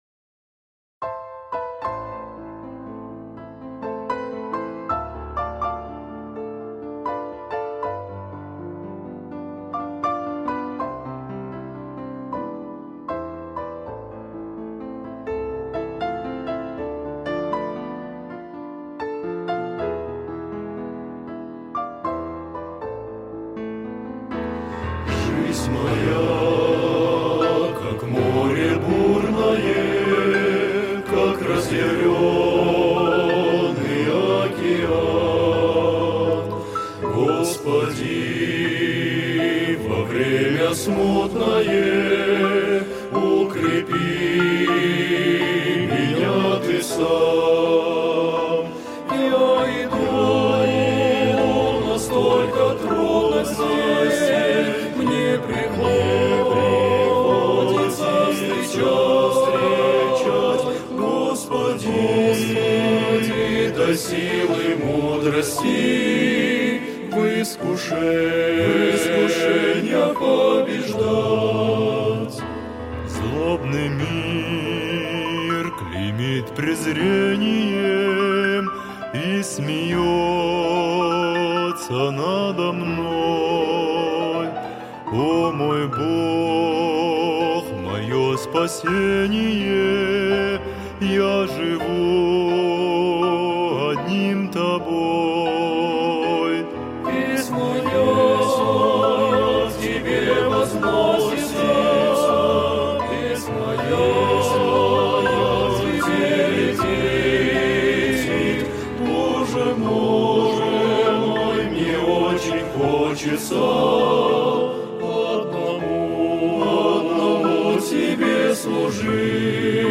196 просмотров 155 прослушиваний 11 скачиваний BPM: 77